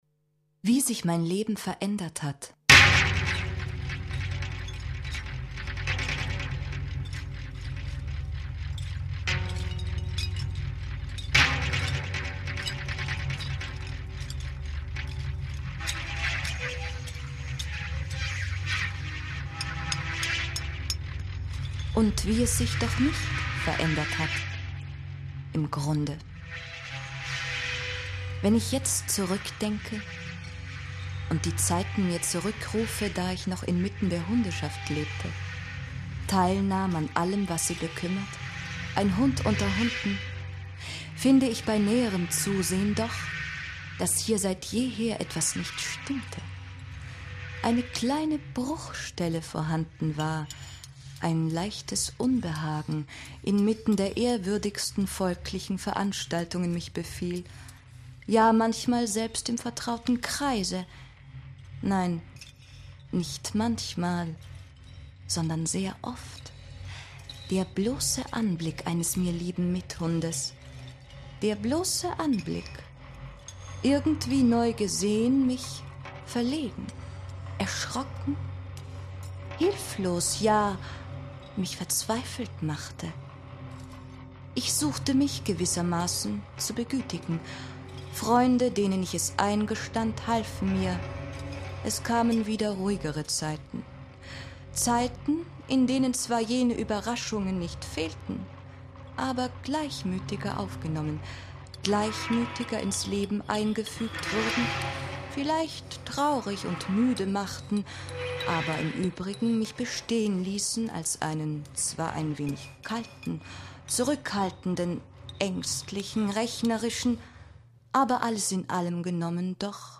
Radio | Hörspiel
Ein Bericht